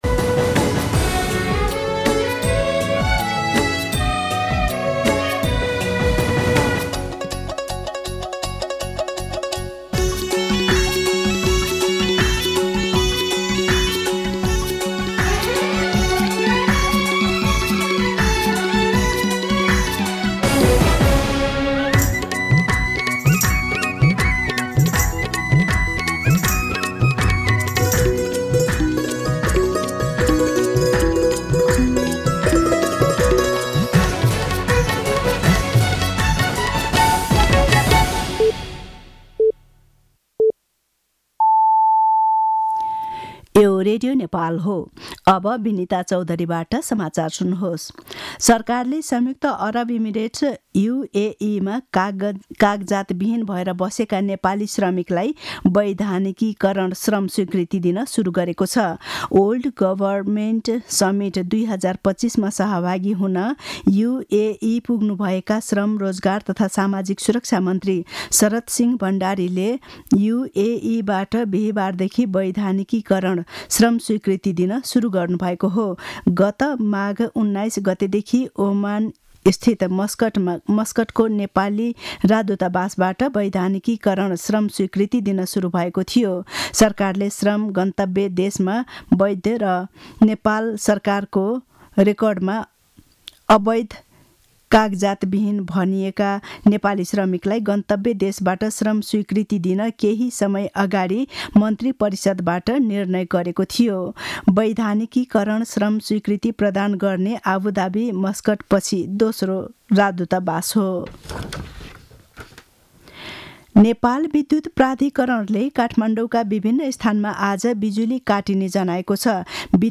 मध्यान्ह १२ बजेको नेपाली समाचार : ४ फागुन , २०८१